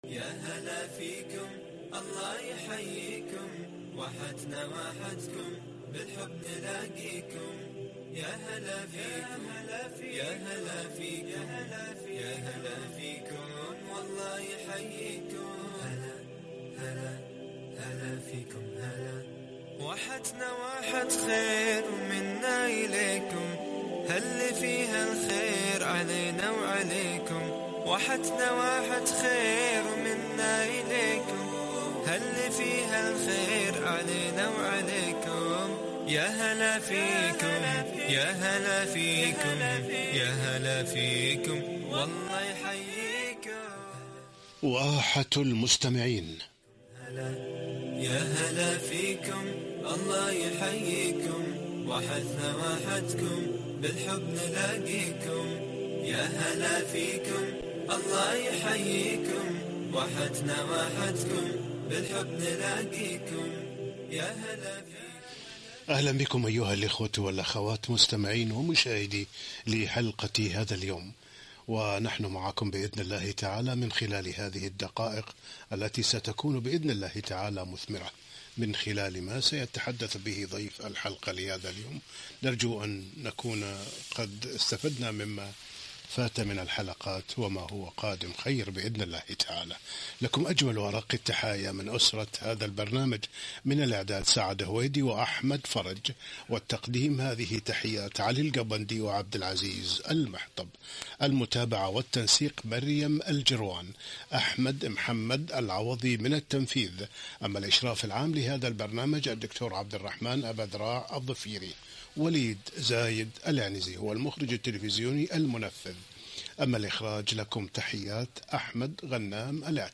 لقاء إذاعي - {وقل لعبادي يقولوا التي هي أحسن}